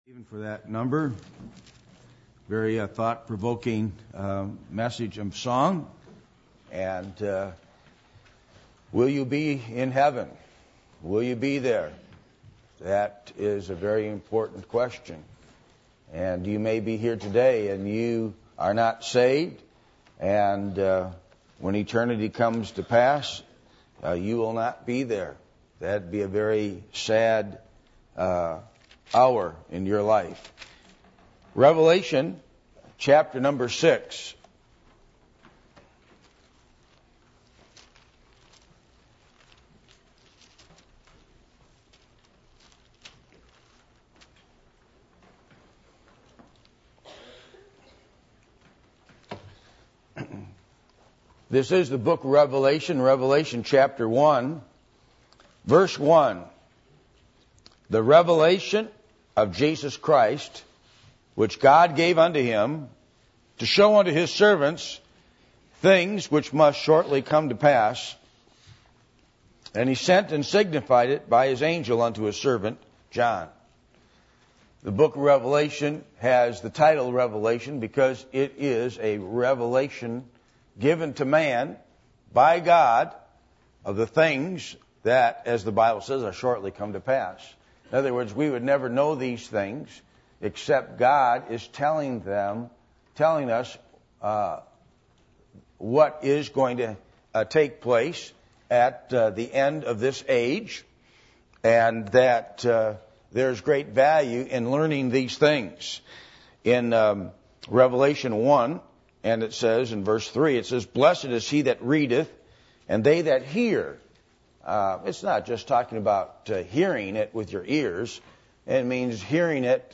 Passage: Revelation 6:1-17 Service Type: Sunday Morning